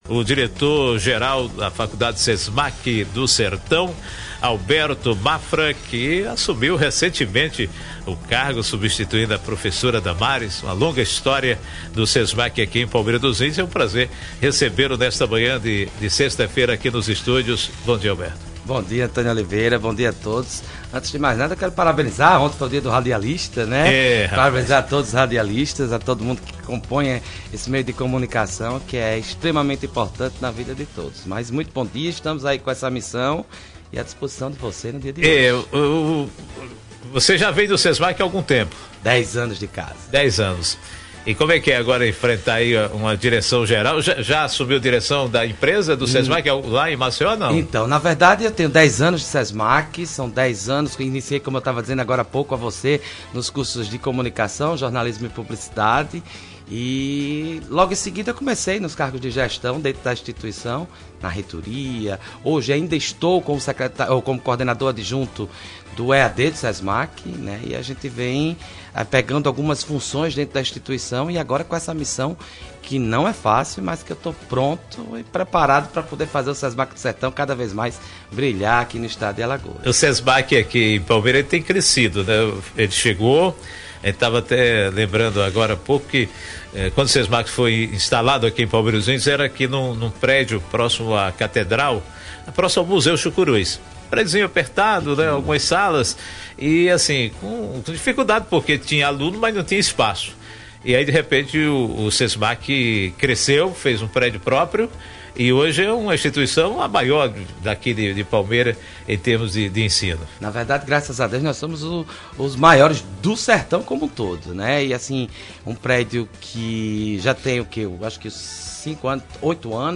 concede entrevista para a Rádio Sampaio 94.5 FM
esteve nos estúdios da Rádio Sampaio 94.5 FM para conceder uma entrevista exclusiva para o programa Nosso encontro.